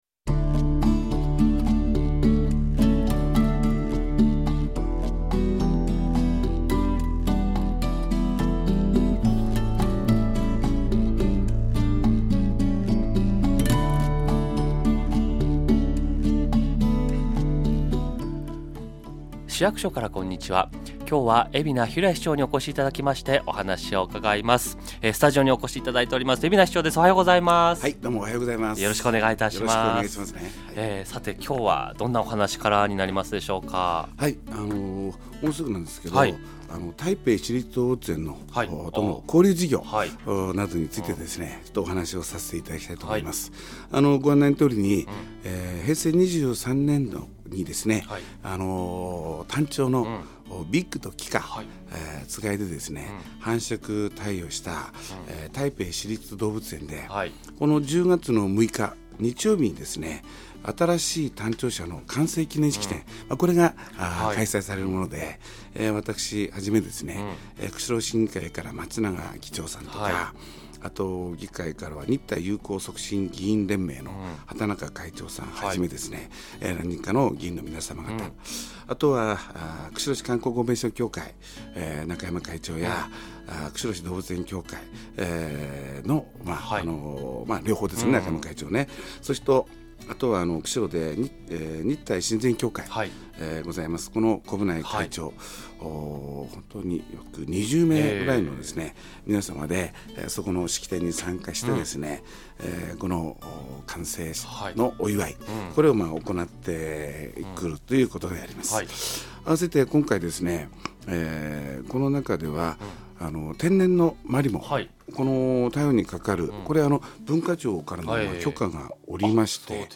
市長が出演した番組を掲載しています